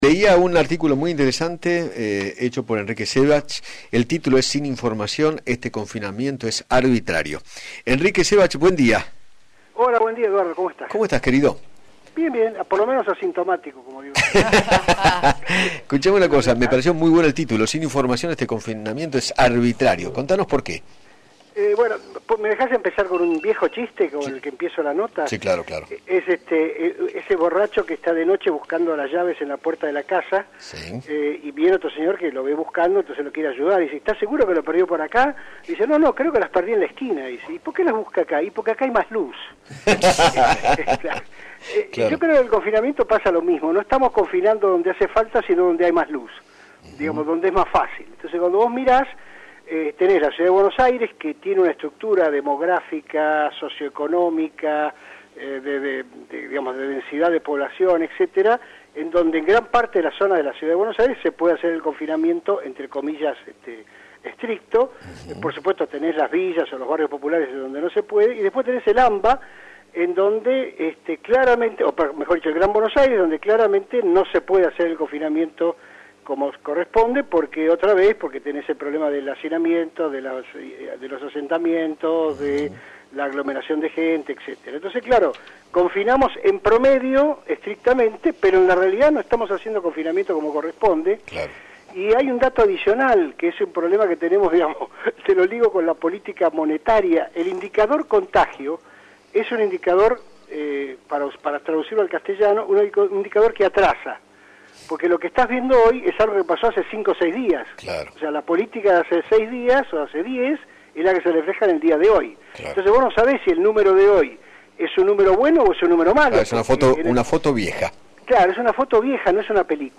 El economista Erique Szewach, ex Director del BCRA y ex vice del Banco Nación, dialogó con Eduardo Feinmann sobre la extensa cuarentena y se refirió a las consecuencias económicas que traerá. Además, habló acerca de la negociación de la deuda con los bonistas.